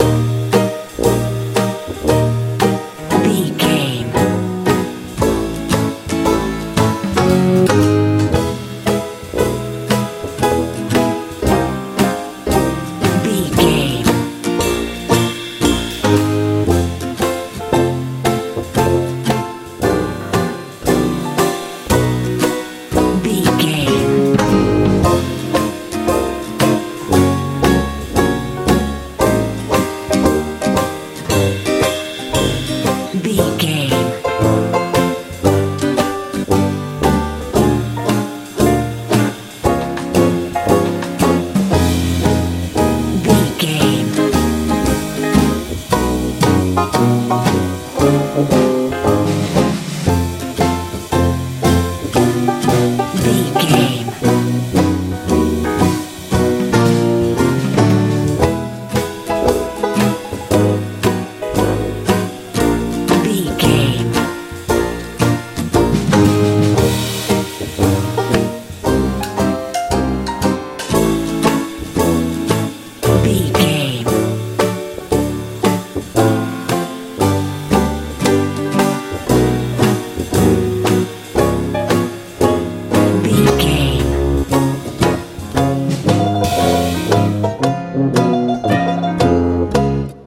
dixieland feel...also tuba in song
Ionian/Major
E♭
wholesome
light
banjo
piano
acoustic guitar
drums
bass guitar
sweet
strange
playful